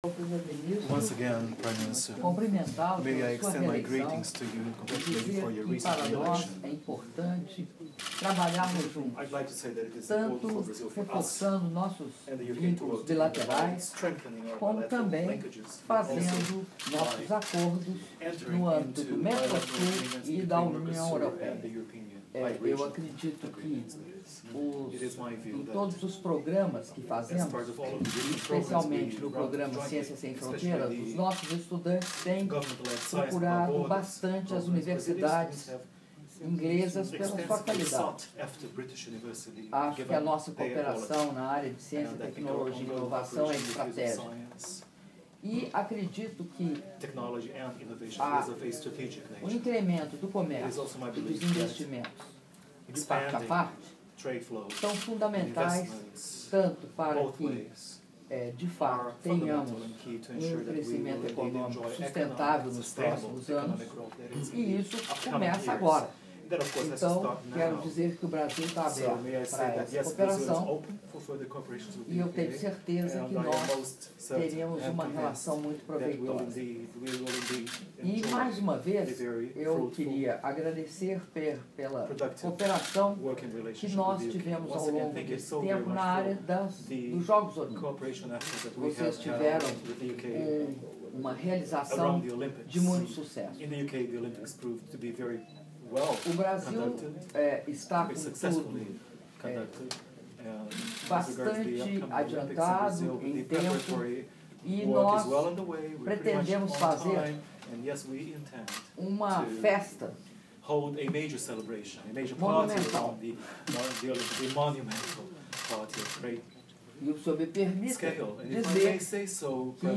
Áudio da declaração à imprensa da presidenta da República, Dilma Rousseff, antes da reunião bilateral com o primeiro-ministro do Reino Unido, David Cameron - Bruxelas/Bélgica (02min25s)